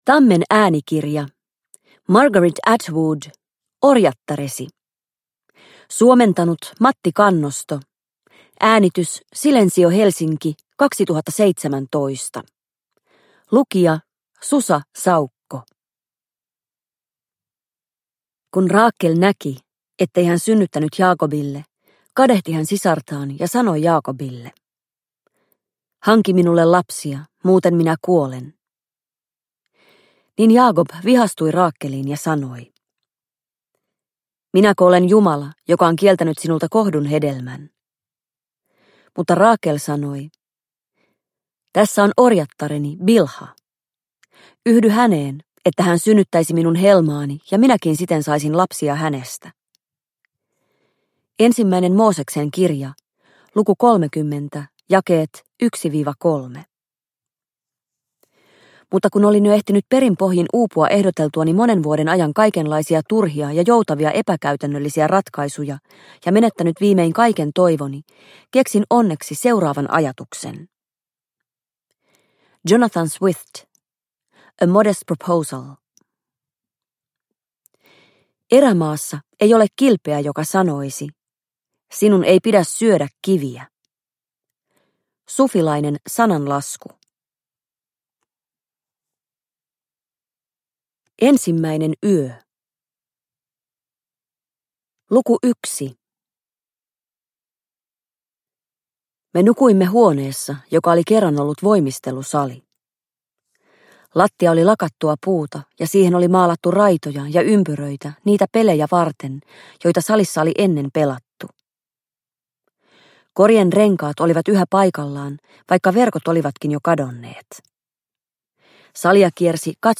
Orjattaresi – Ljudbok – Laddas ner